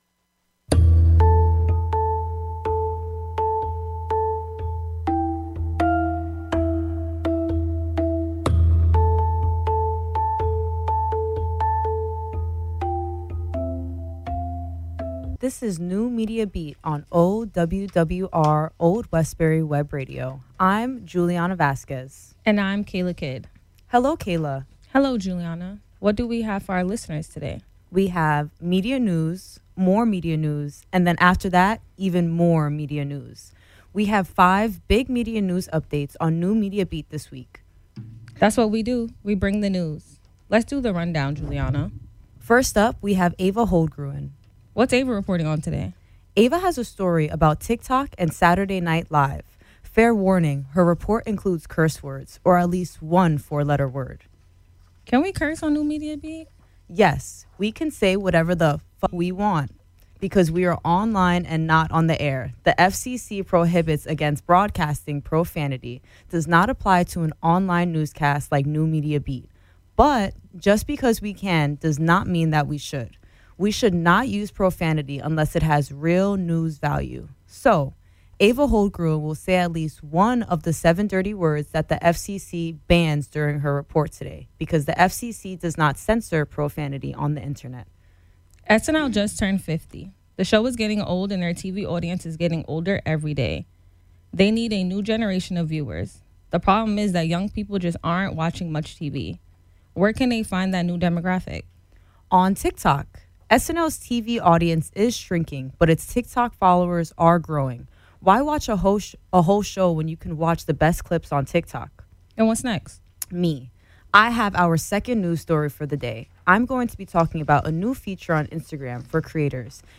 New Media Beat covers new media news from around the world. NMB is produced by students from SUNY Old Westbury.